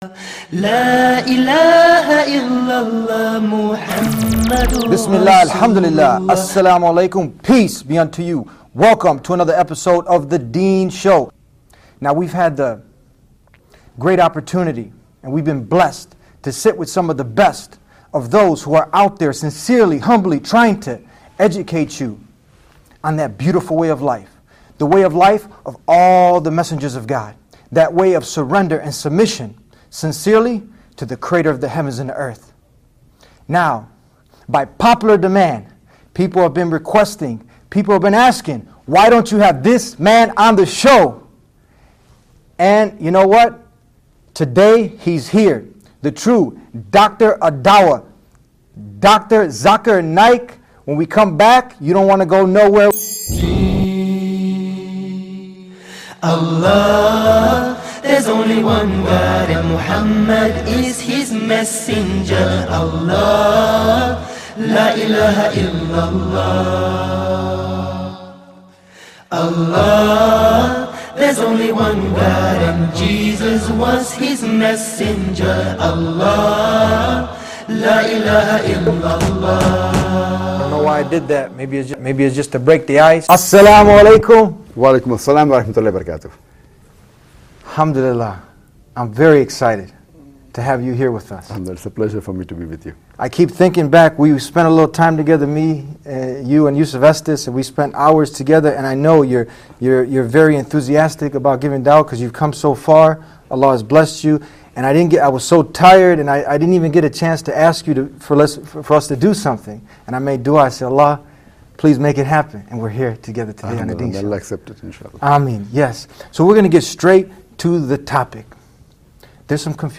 Dr. Zakir Naik is our guest on today’s show, Dr. Zakir is the President of Islamic Research Foundation, He is a dynamic international orator on Islam and Comparative Religion.